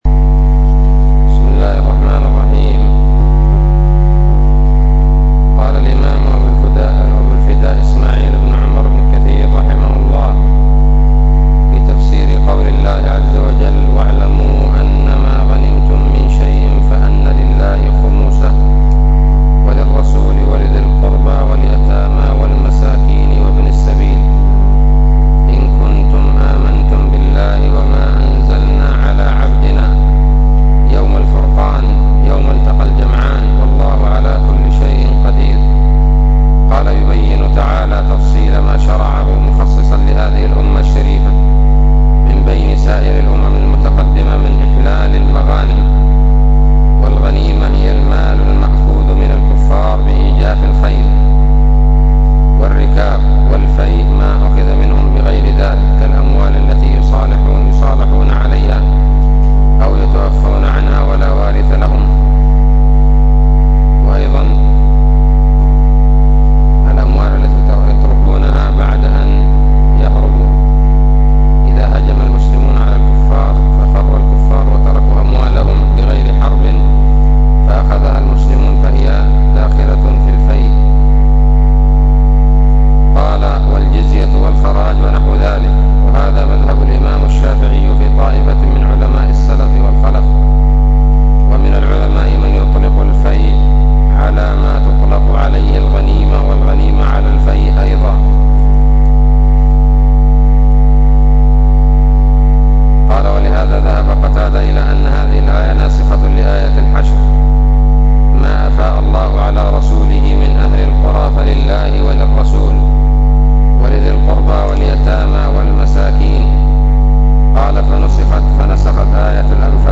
الدرس الثالث والعشرون من سورة الأنفال من تفسير ابن كثير رحمه الله تعالى